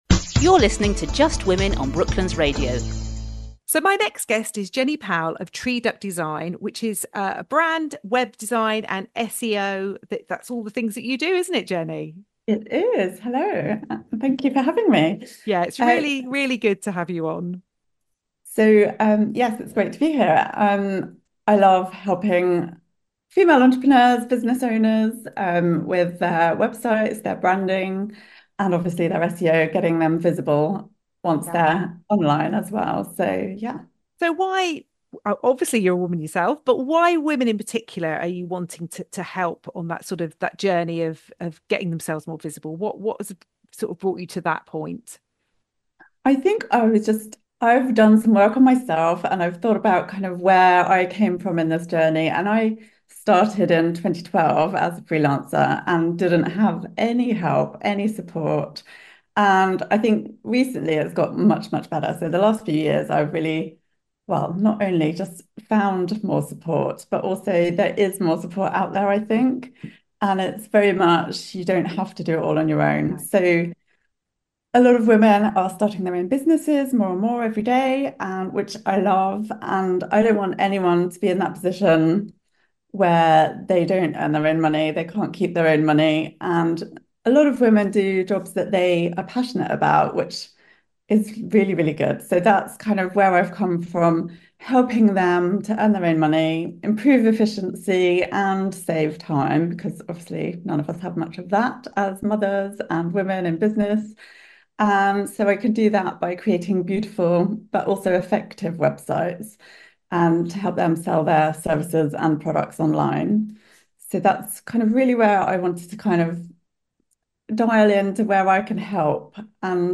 Brooklands Radio Just Women Interview